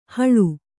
♪ haḷu